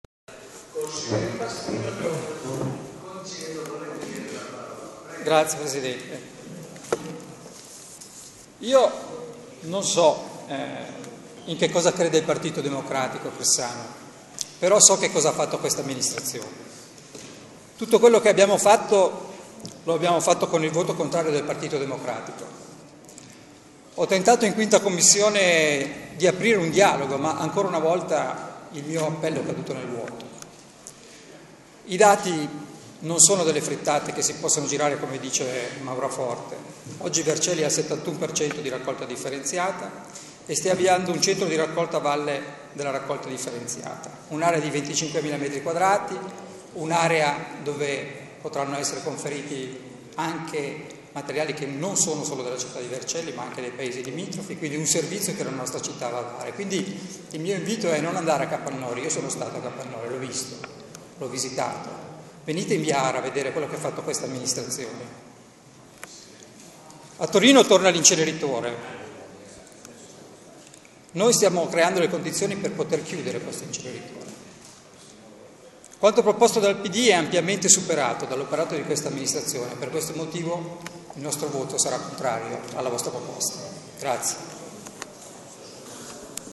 Intervento del Consigliere Stefano Pasquino al Consiglio Comunale di Vercelli.
Ascolta di seguito l’intervento in Consiglio Comunale di Stefano PASQUINO 19/06/2013 – dichiarazione di voto